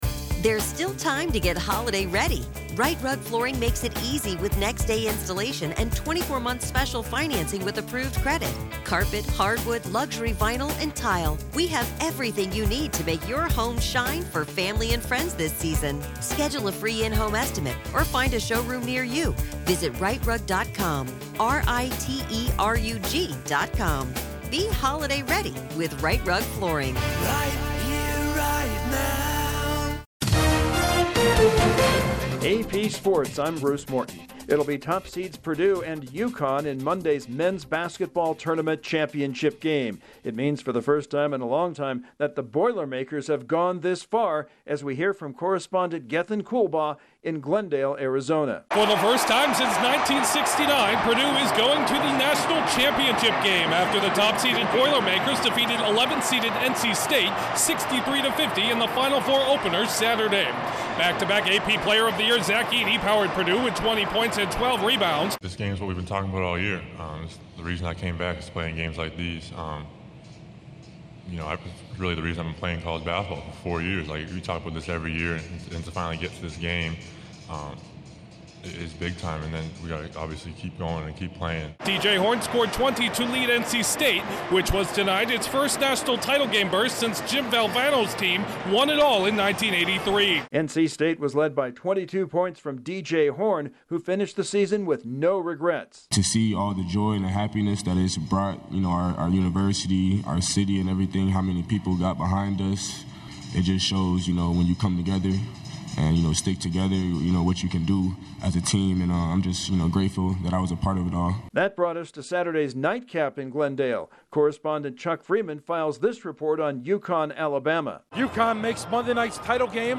The NCAA men's title game is set, the Lakers stay hot, the Bruins expand their division lead and a new Dodgers hurler is impressive. Correspondent